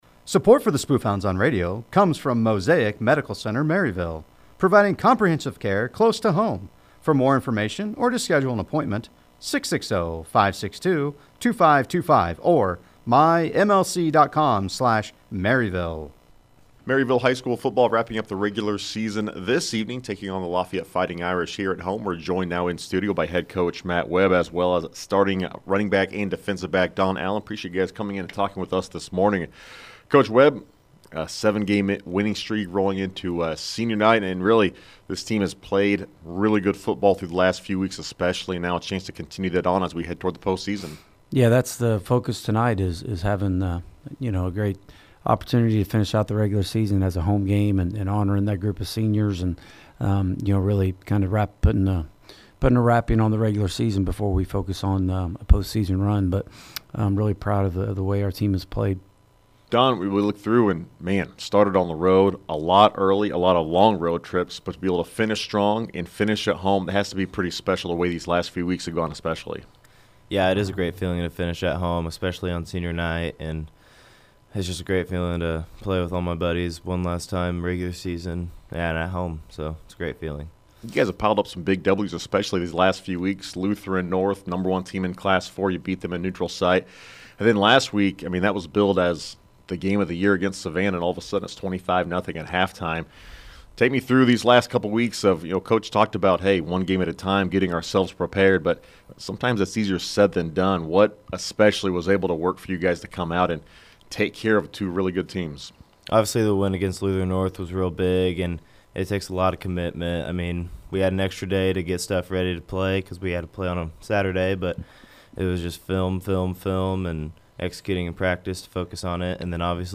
Local Sports